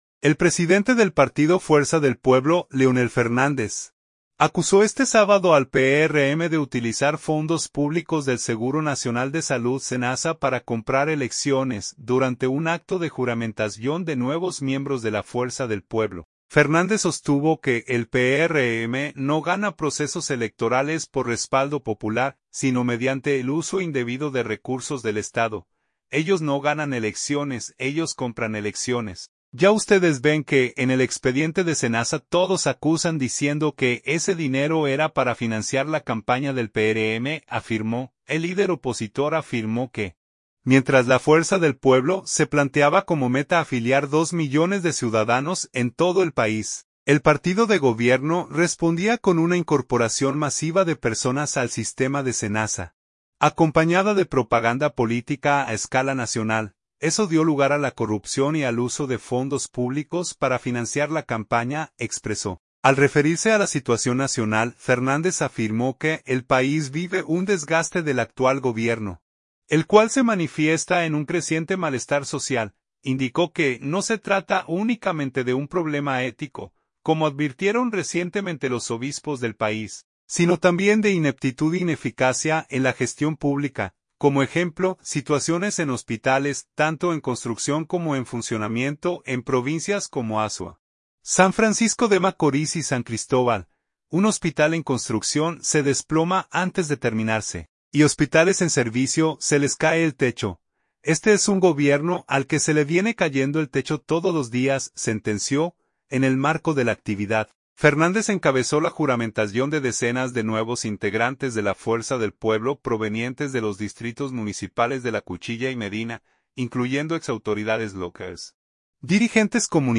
Leonel Fernández durante el acto
Durante un acto de juramentación de nuevos miembros de la Fuerza del Pueblo, Fernández sostuvo que el PRM no gana procesos electorales por respaldo popular, sino mediante el uso indebido de recursos del Estado.